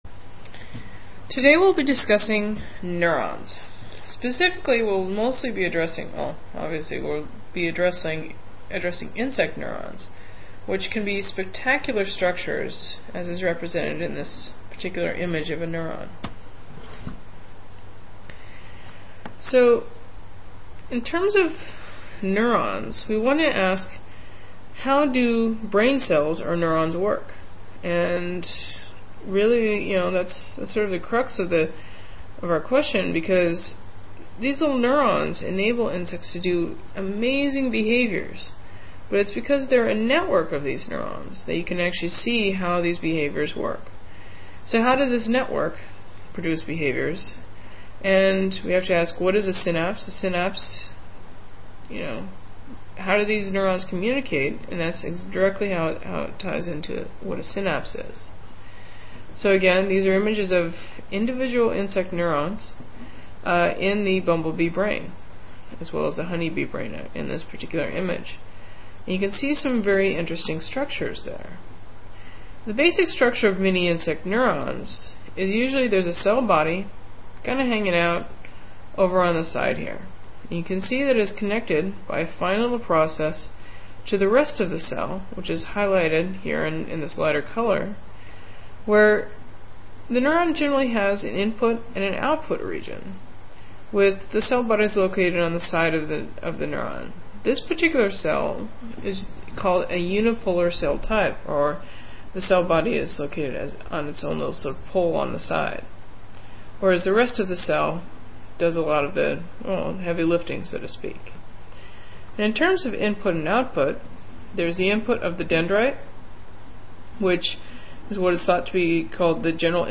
Mini-lecture: